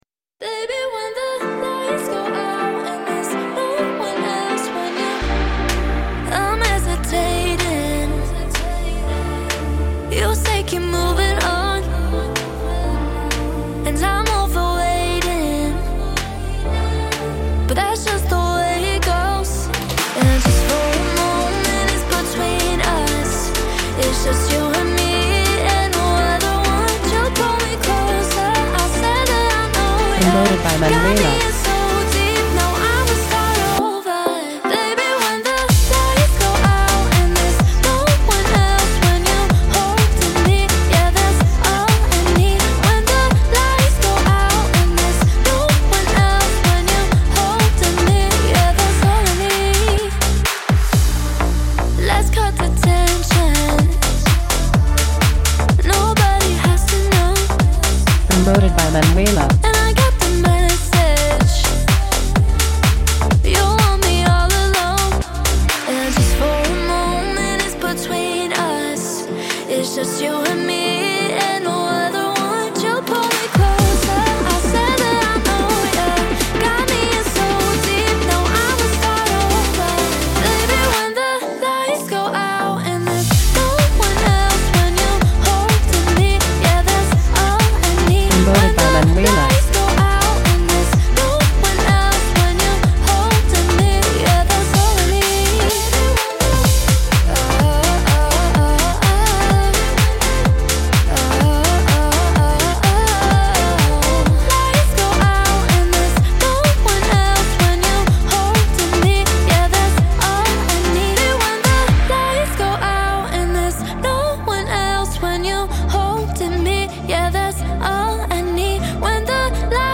un morceau idéal pour la piste de danse.
Radio Edit